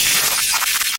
Glitch Effect
A digital glitch with buffer stutters, bit-crush artifacts, and data corruption sounds
glitch-effect.mp3